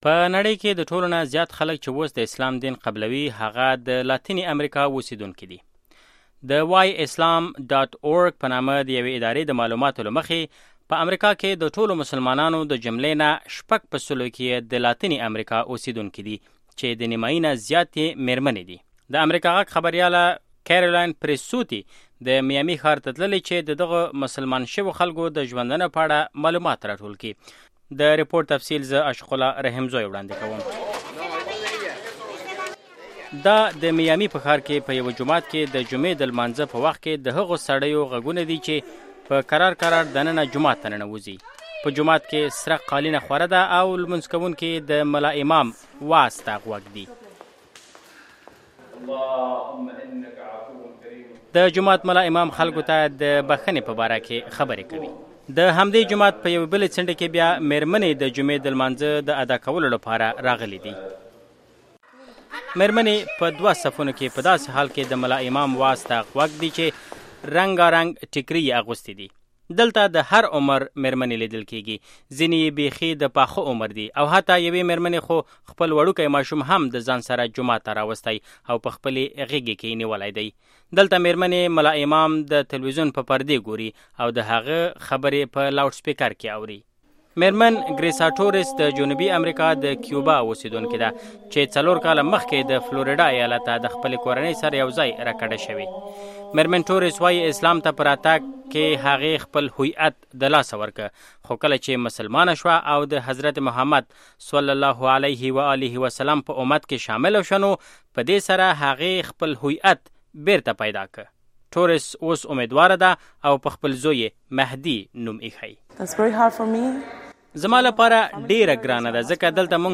بشپړ رپوټ دلته اوریدلی شئ